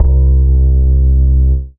Fat Upright Bass (JW3).wav